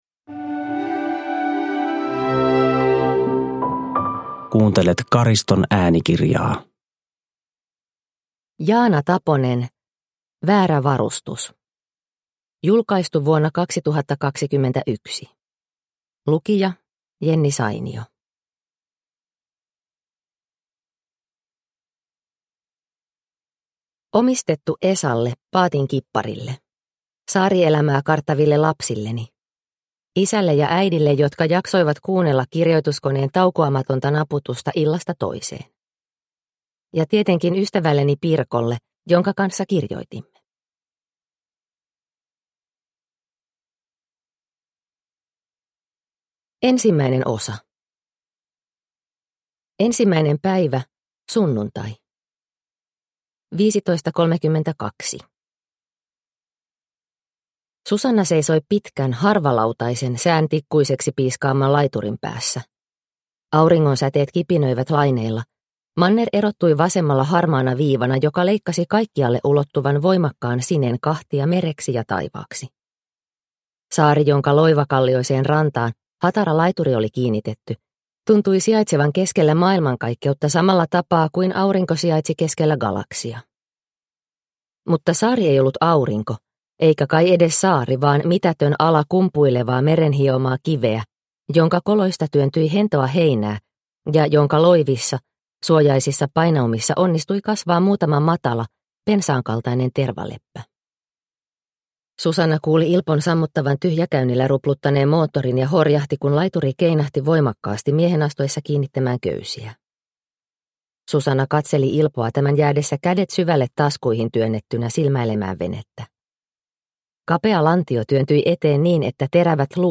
Väärä varustus (ljudbok) av Jaana Taponen